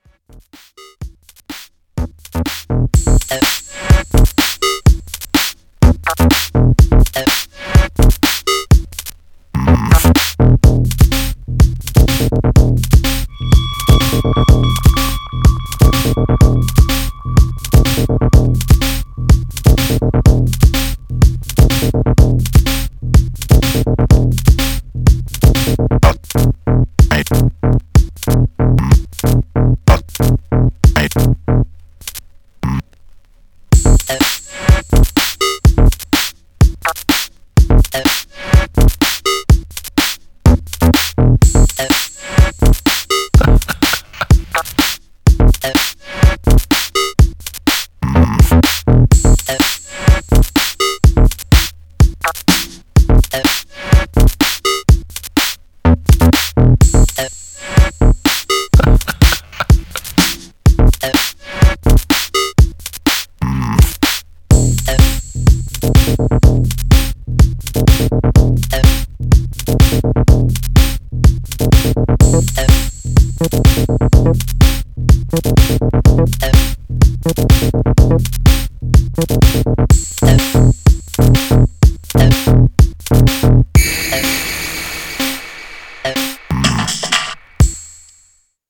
Styl: Electro, House, Techno, Breaks/Breakbeat